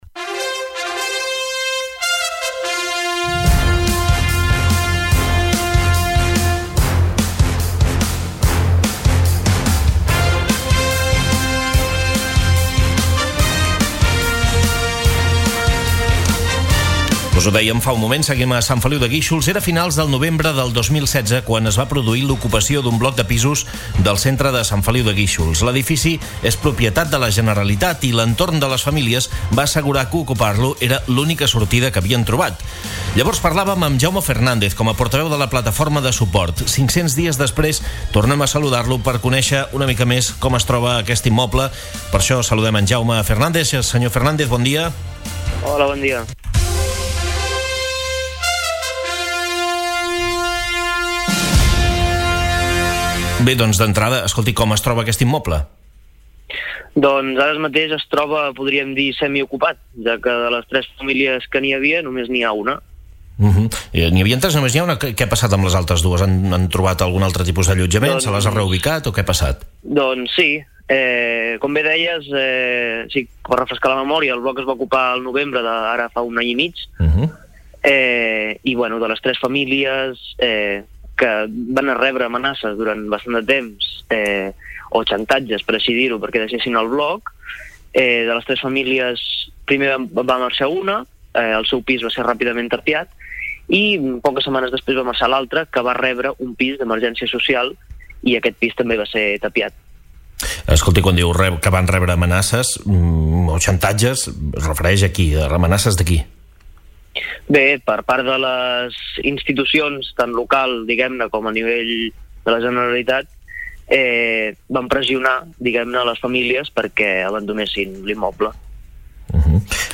Entrevista sencera: